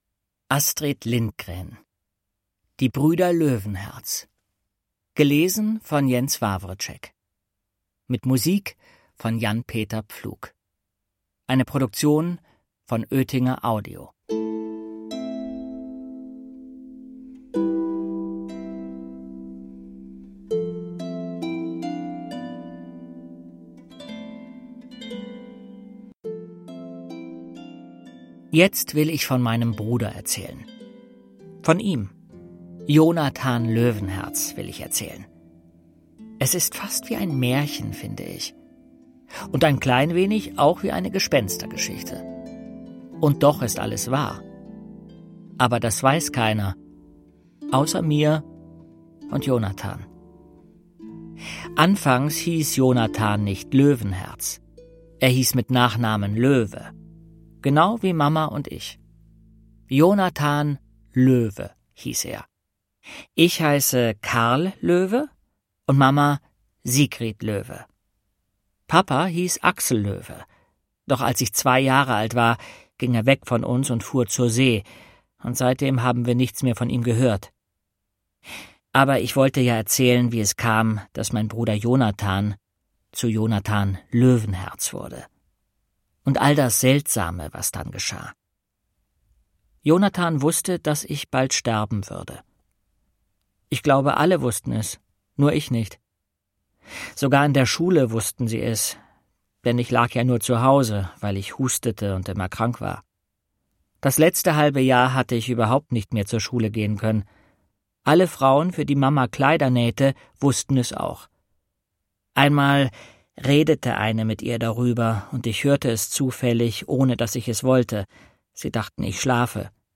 Jens Wawrczeck (Sprecher) Audio-CD 2015